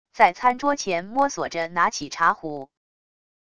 在餐桌前摸索着拿起茶壶wav音频